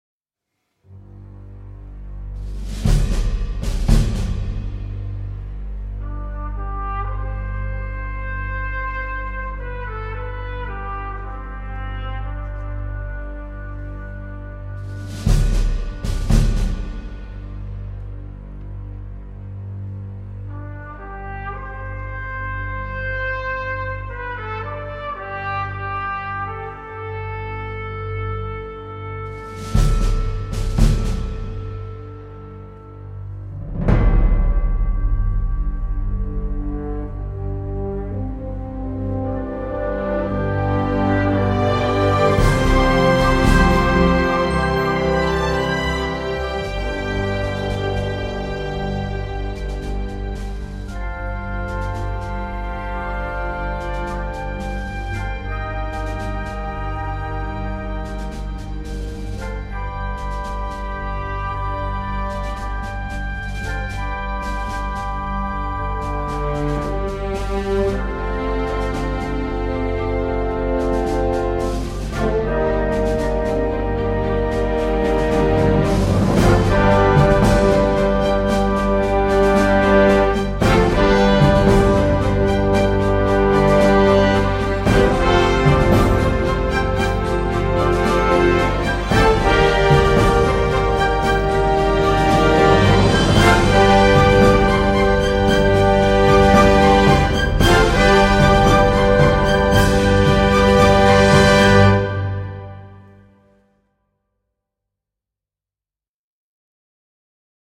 Le suspense et l’action sont un peu génériques.